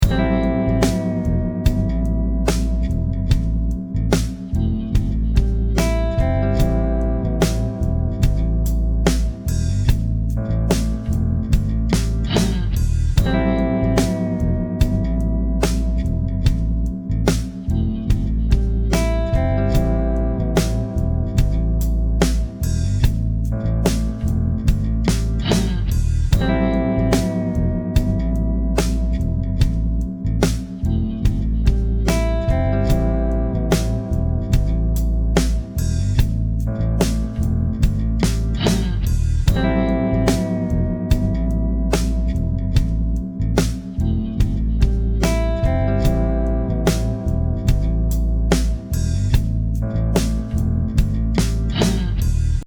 Carissimi qui prima di tutto relativo alla lezione di oggi metto a disposizione da utilizzare le parti in loop da poter utilizzare, ovvero gli accordi suonati lentamente.